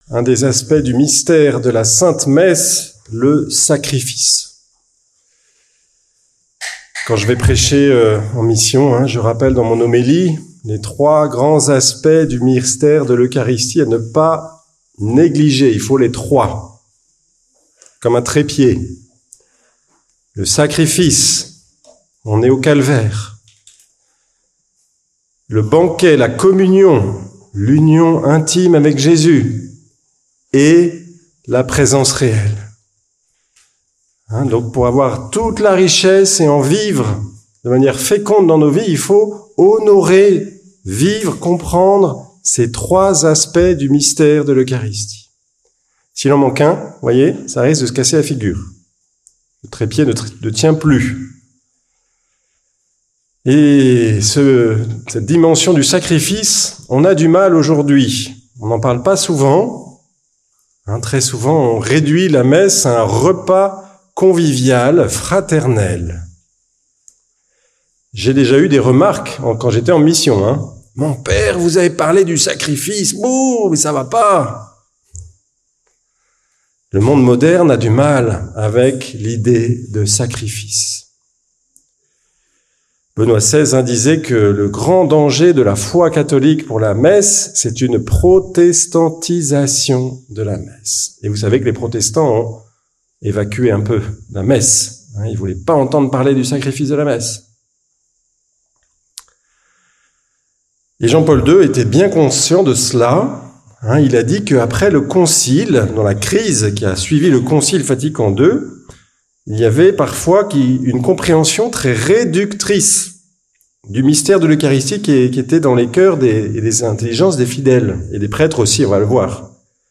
Toulon - Adoratio 2024